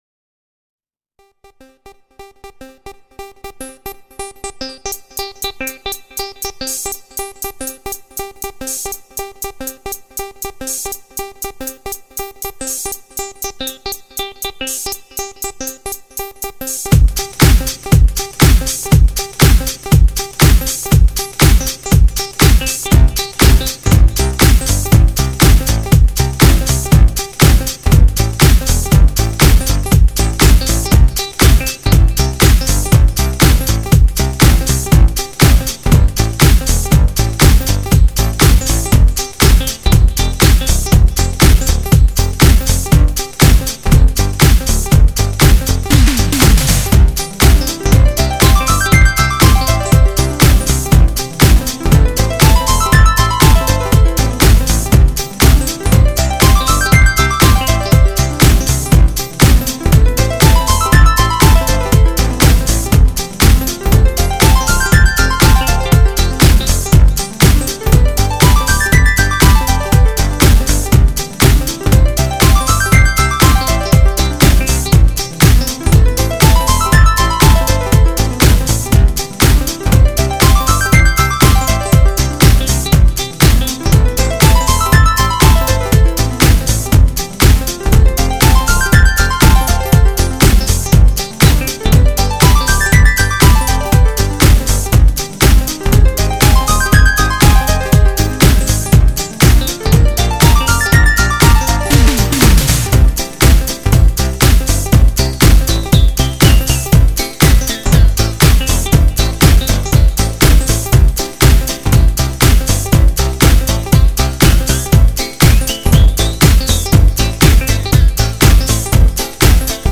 This remix is a DJ mix of some video game series' tunes.